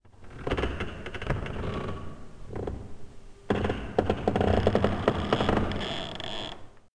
woodcreak2e.wav